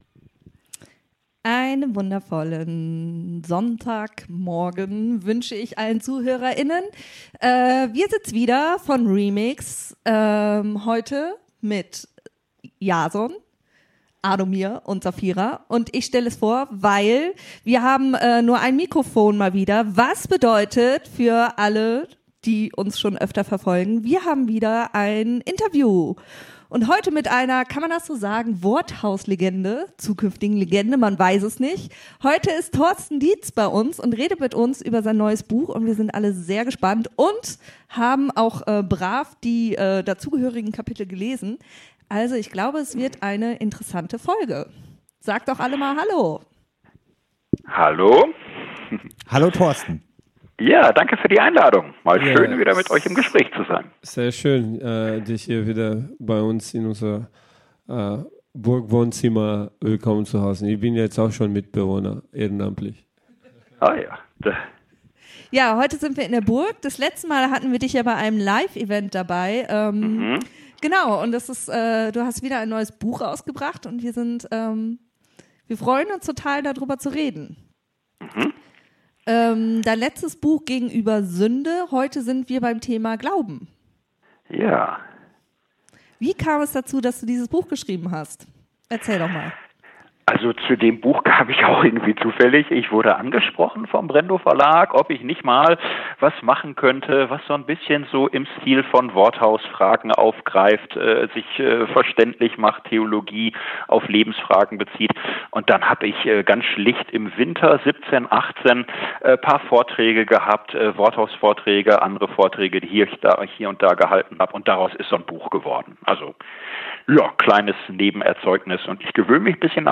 Episode 70 – Kann man fundiert unfundamentalistisch glauben? Interview